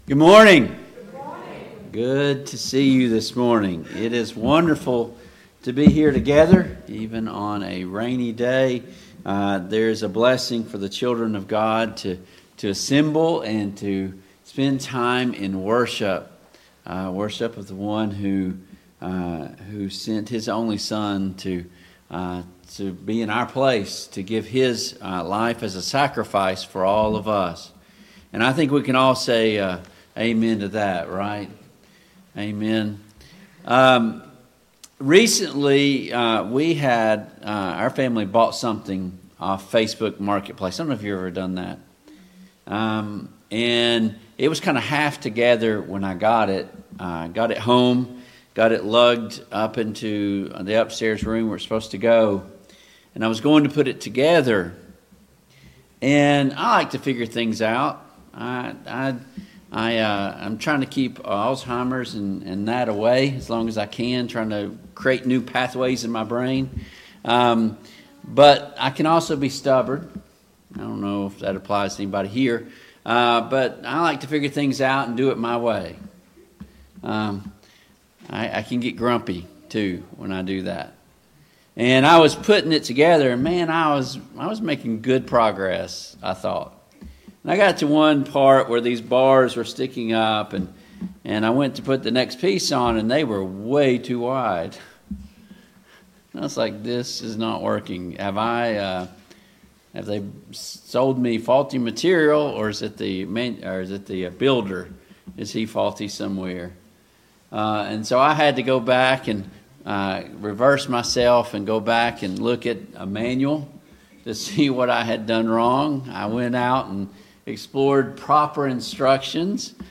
2 Peter 3:15-18 Service Type: AM Worship Download Files Notes Topics: Authority , Biblical Authority « 11.